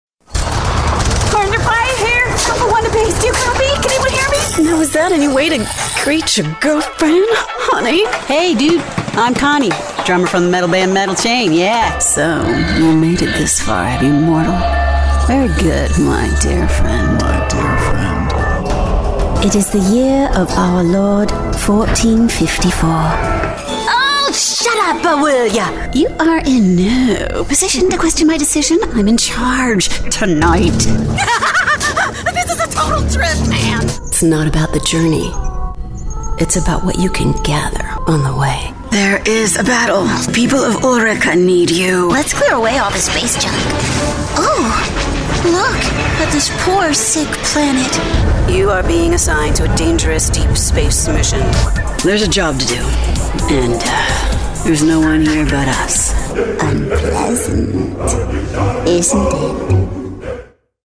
Warm, Dynamic, Versatile, Textured voice. Female voice actor. American.
Sprechprobe: Sonstiges (Muttersprache):